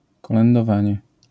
This type of caroling is called "kolędowanie" in Poland [kɔlɛndɔˈvaɲɛ]